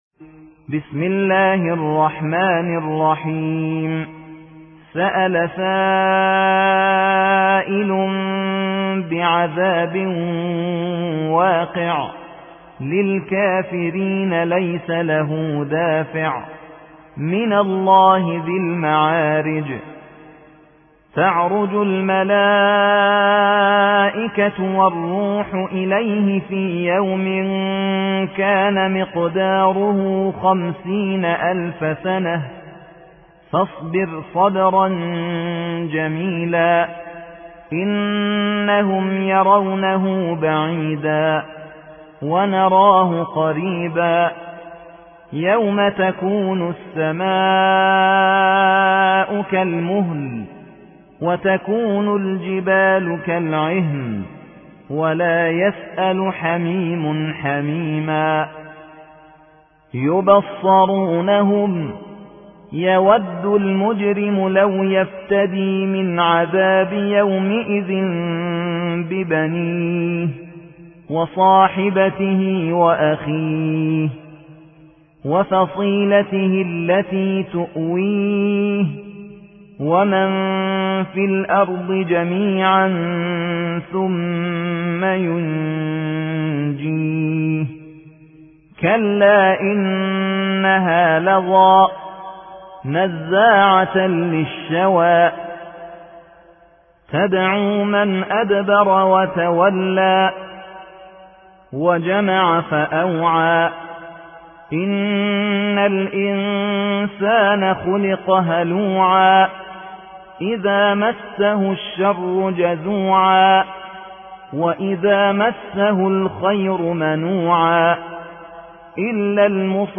70. سورة المعارج / القارئ